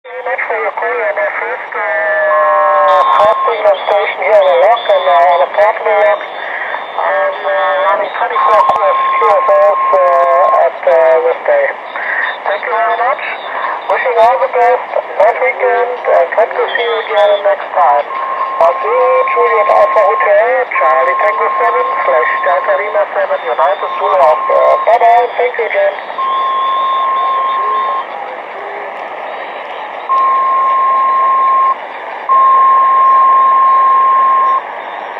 Es ging einige Zeit erstaunlich gut in SSB auf 3683 Khz mit Signalstärken um S6-7.
Sein RX besteht aus einem Sangean ATS909 mit 25 m Wire-Antenne.